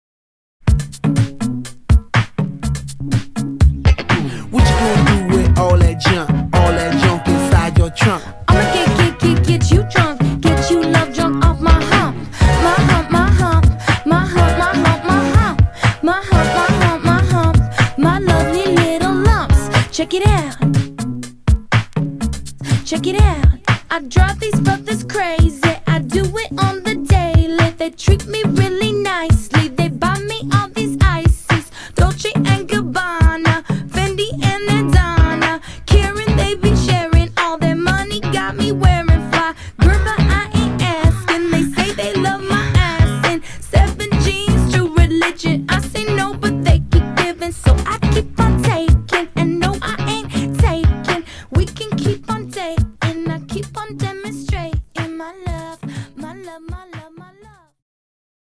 123 bpm
B-Side blend that I made a long time ago.